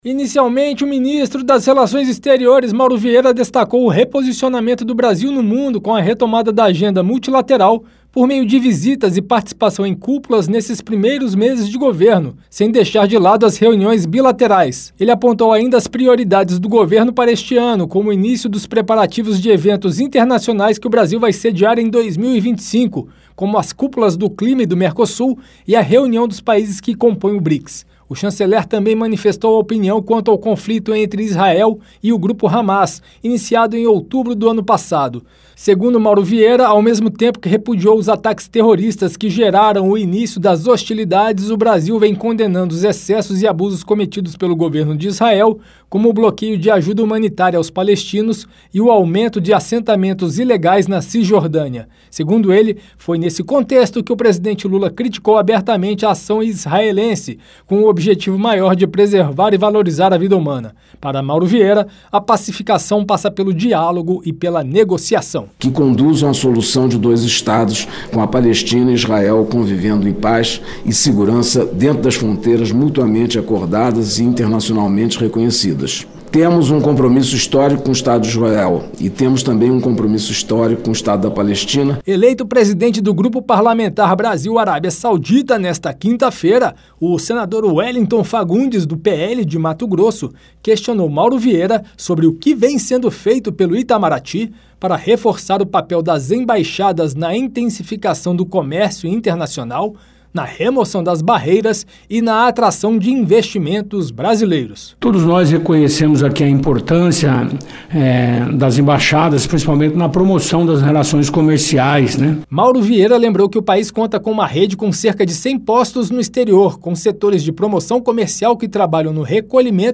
Em audiência pública na Comissão de Relações Exteriores e Defesa Nacional, o chanceler Mauro Vieira destacou as ações em política externa implementadas pelo governo brasileiro desde o início do ano passado.